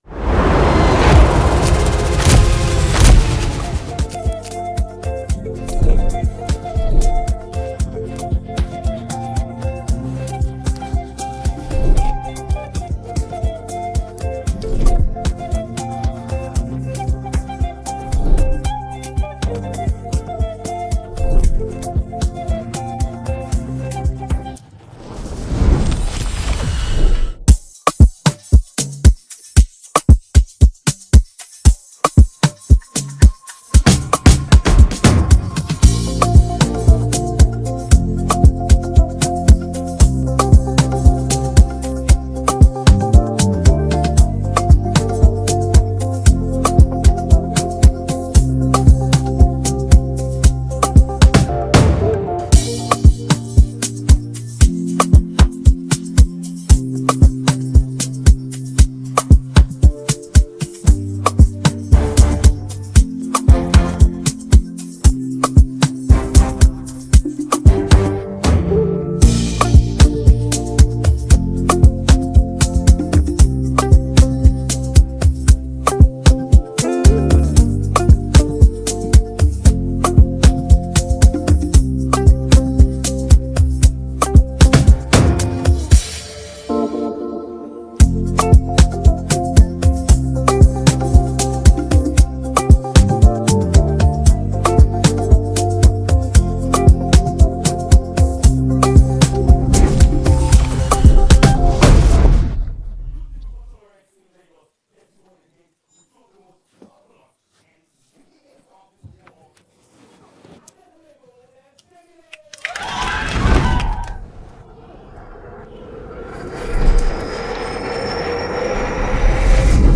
Afro popAfrobeats